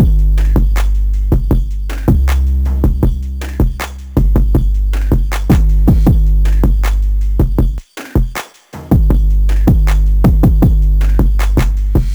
• Dirty Phonk BIT Drum Loop Gm 158.ogg
Hard punchy kick sample for Memphis Phonk/ Hip Hop and Trap like sound.